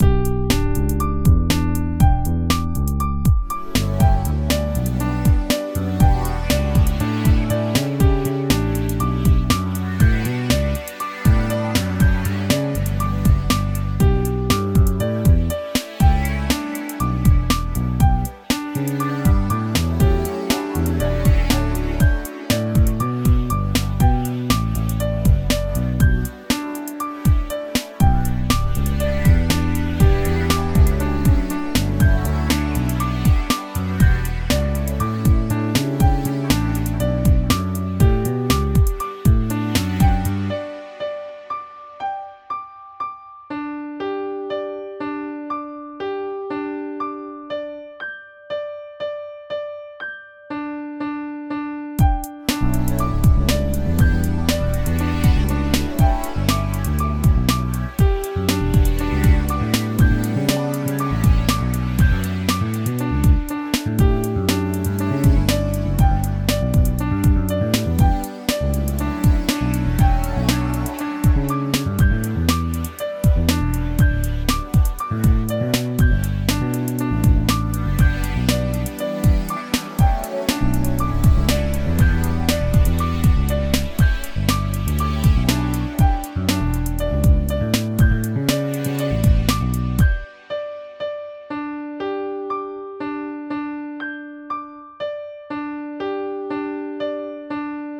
Remix of click.mid
Here is a remix I made of the metronome test (click.mid) that comes with the Ardour Ubuntu package: